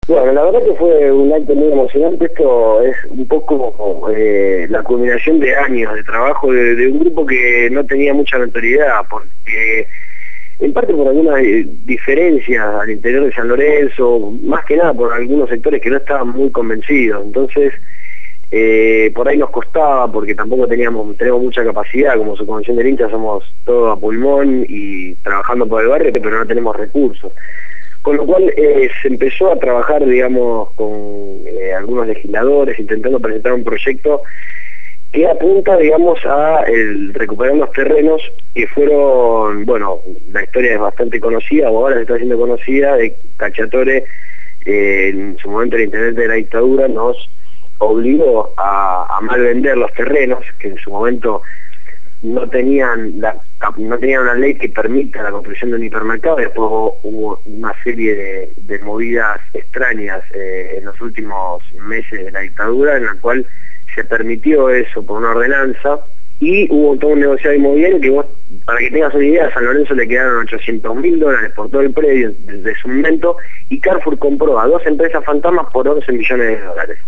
dialogó con el programa «Desde el barrio» (lunes a viernes de 9 a 12 horas) por Radio Gráfica FM 89.3